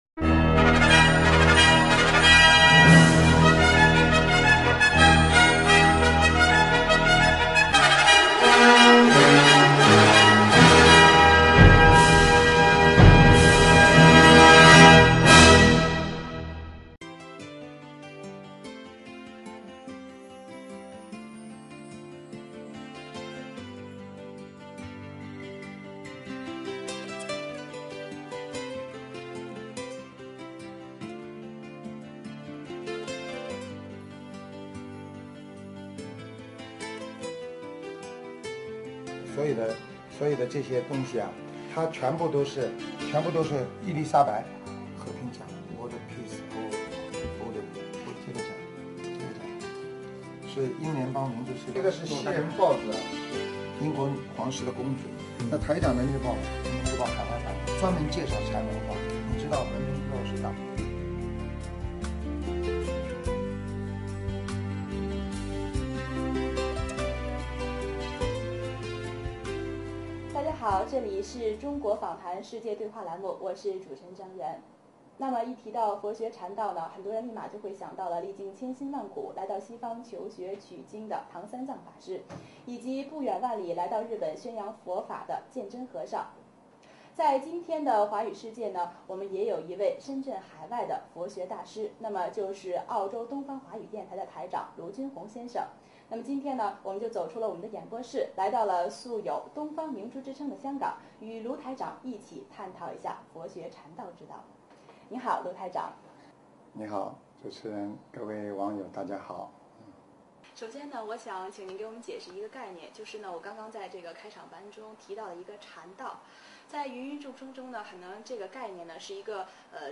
中国访谈•世界对话》栏目采访